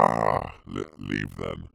Voice Lines
Rahhhhh leave leave then.wav